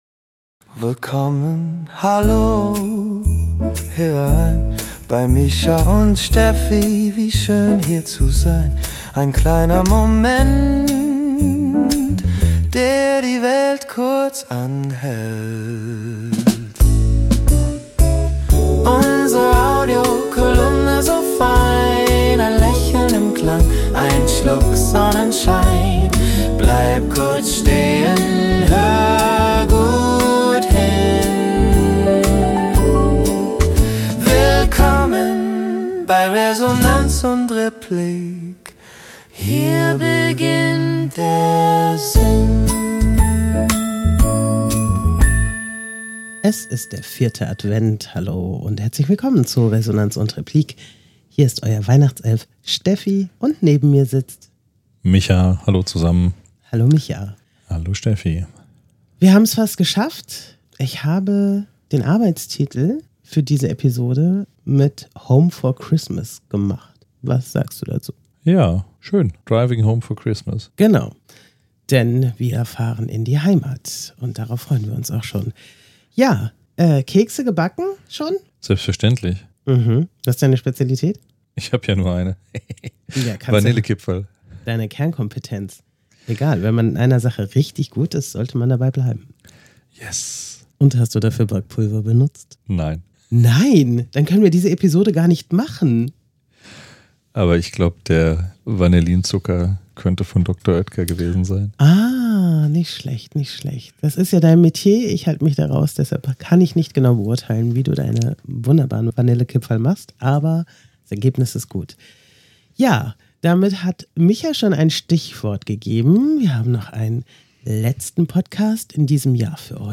Die beiden Hosts tauchen ein in die faszinierenden Höhen und Tiefen dieser Unternehmensgeschichte, die eng mit der deutschen Geschichte verwoben ist.